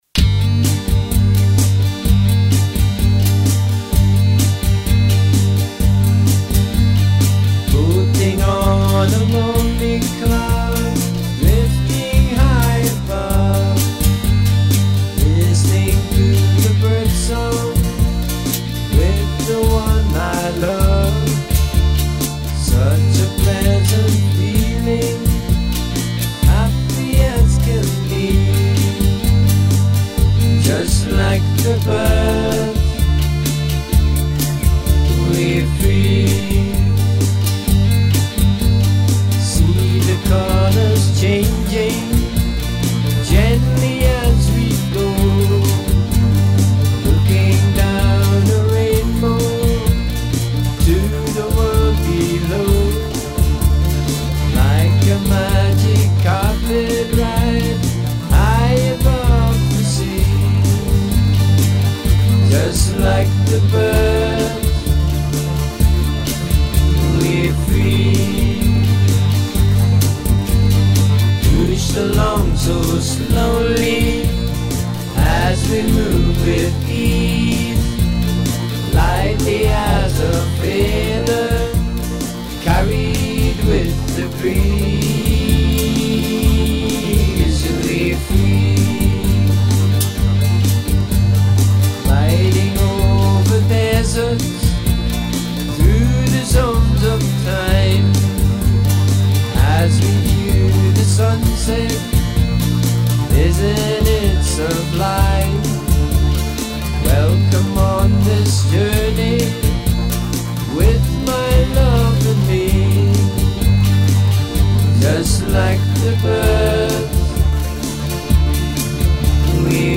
rock music